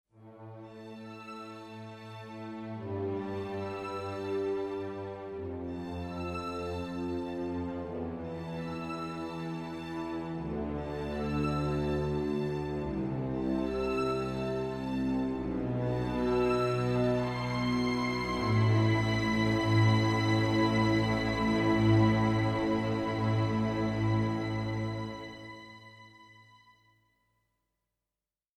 Cue 3 Strings